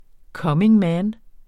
Udtale [ ˈkʌmeŋ ˈmæːn ]